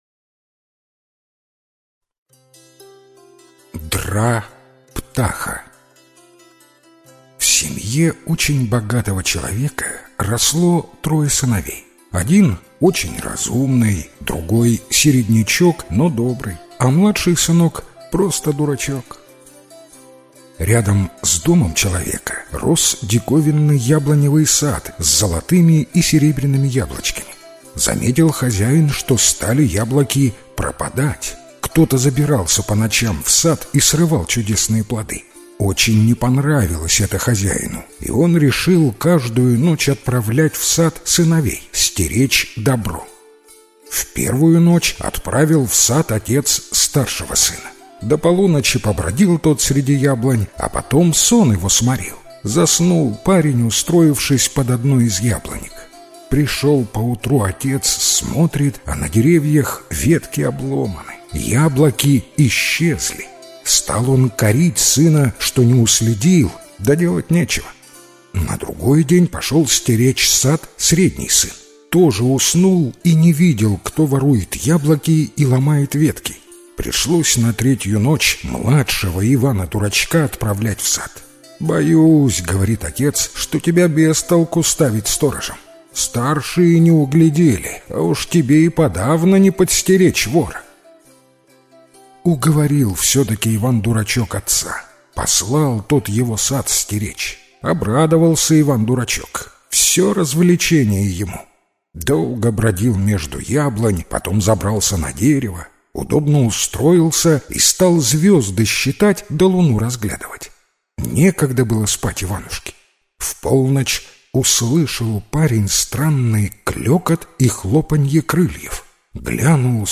Дра-птаха - белорусская аудиосказка - слушать онлайн